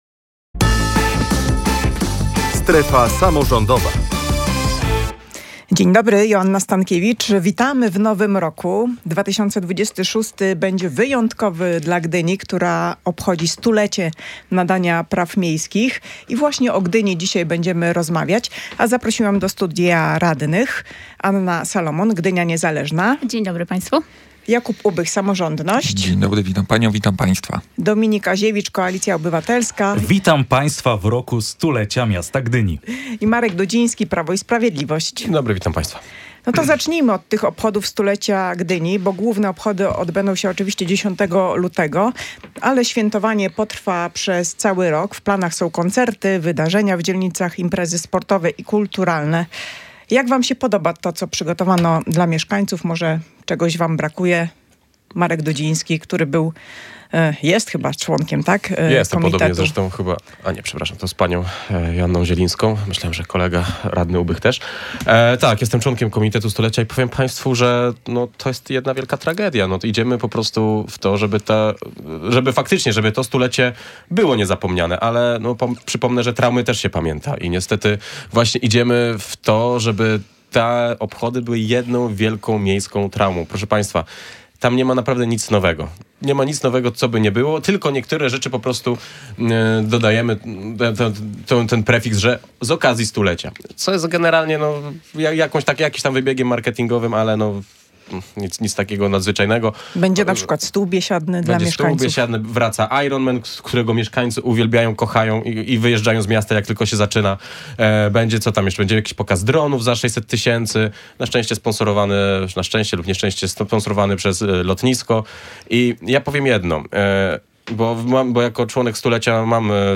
Kontrowersyjny pomysł budowy kolejki linowej łączącej Plac Konstytucji w centrum z północnymi dzielnicami Gdyni zdominował "Strefę Samorządową" w Radiu Gdańsk.